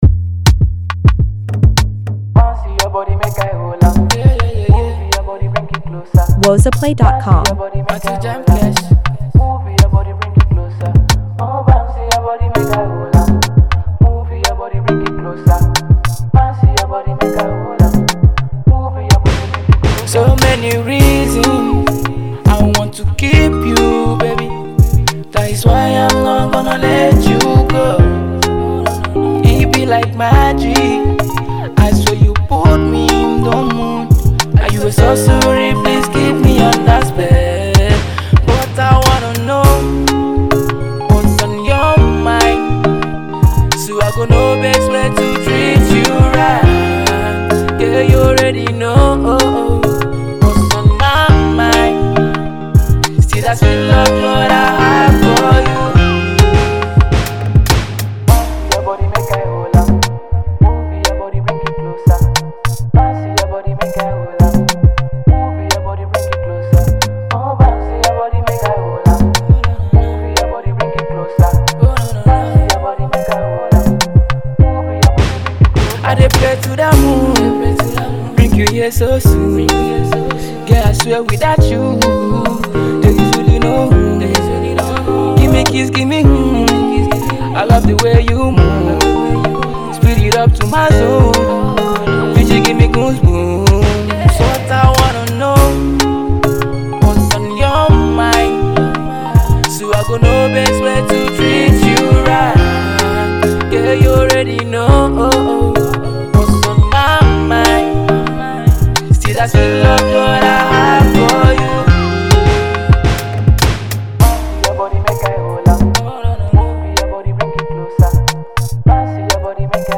emotional love mood sensational